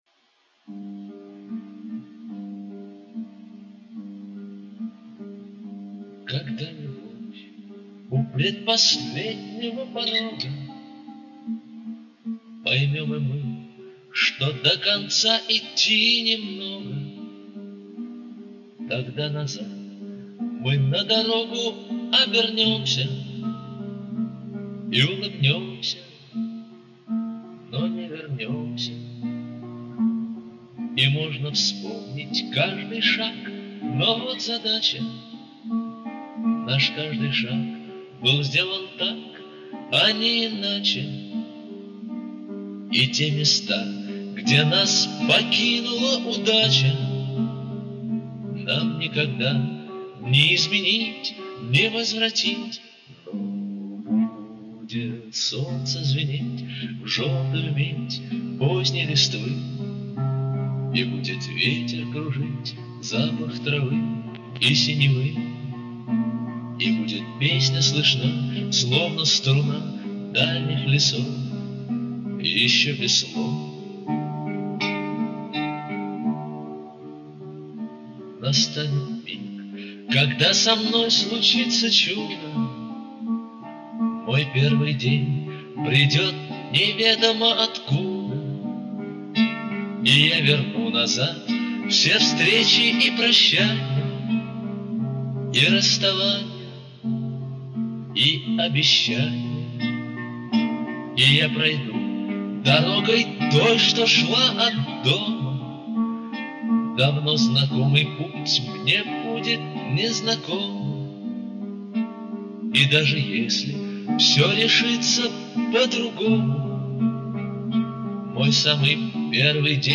Нагло влезу со своей реставрацией..